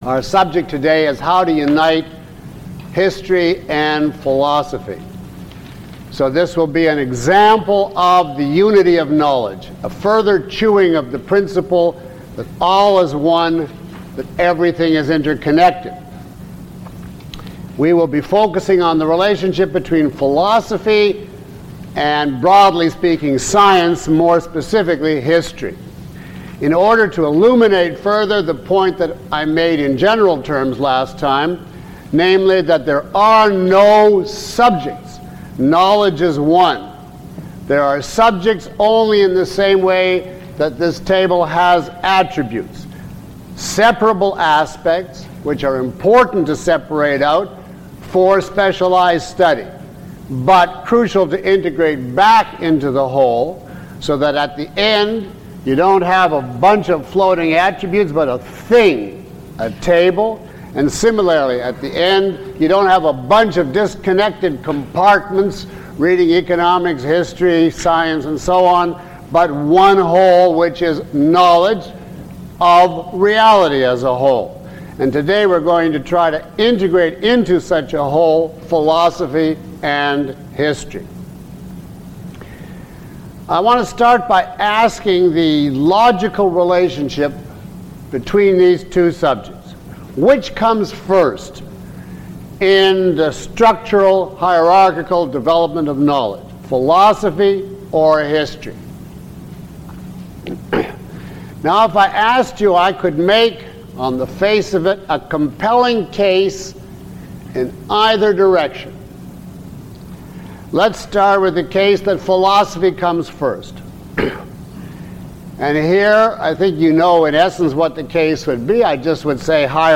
Lecture 02 - Unity in Epistemology and Ethics.mp3